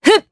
Seria-Vox_Attack1_jp.wav